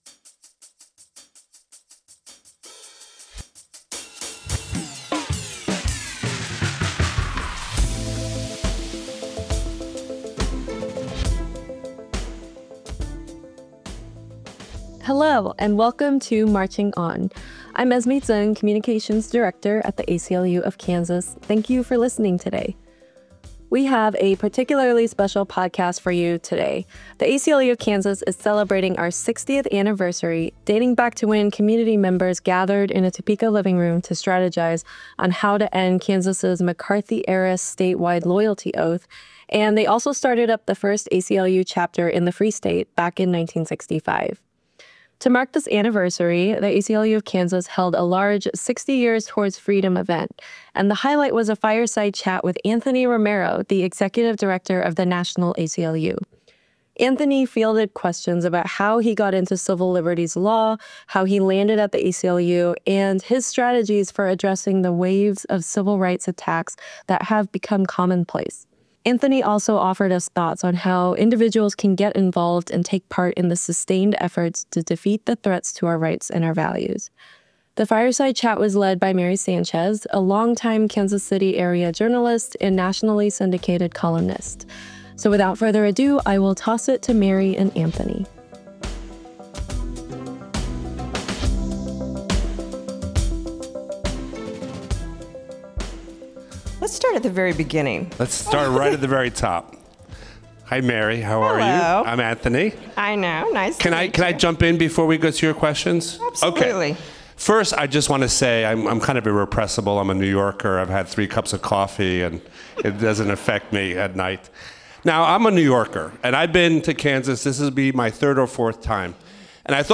Fireside Chat with Anthony Romero